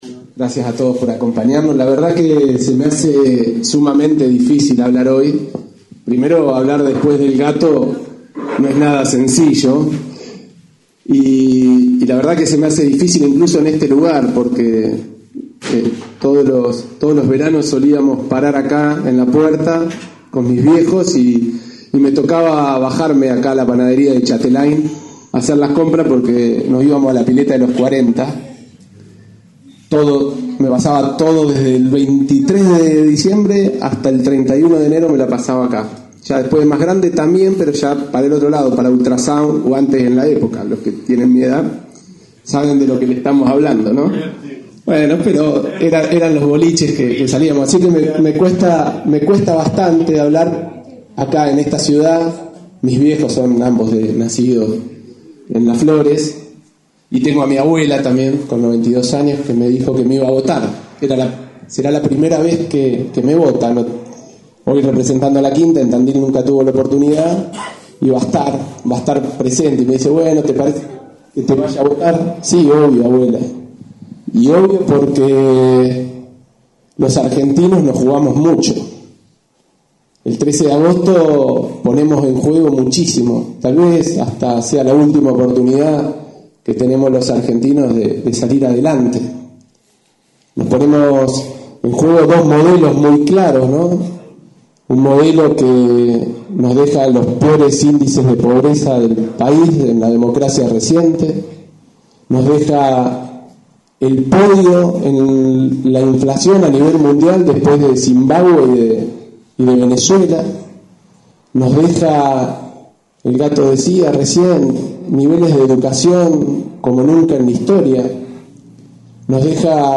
Con la presencia de distintos dirigentes y pre candidatos de la 5ta sección electoral, se inauguró el bunker electoral en la ex panadería Chateláin en calle San Martin al 146.